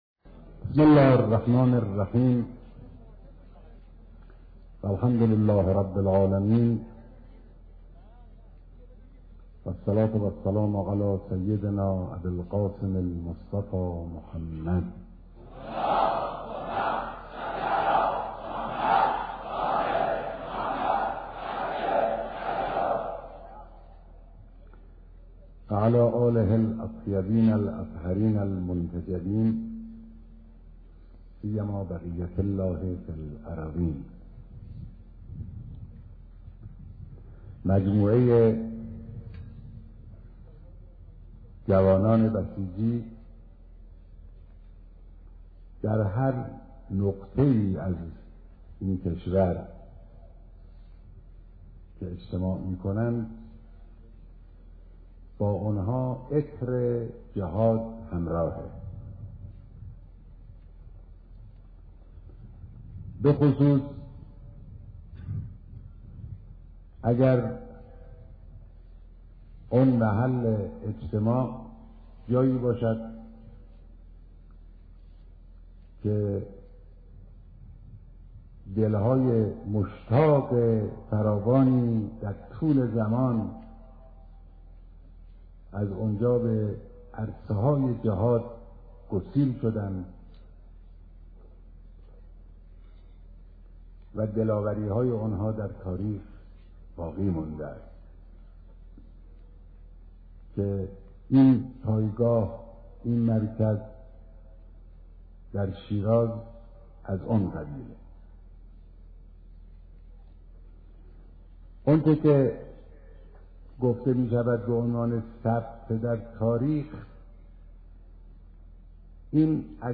دیدار هزاران نفر از بسیجیان و سپاهیان پاسدار استان فارس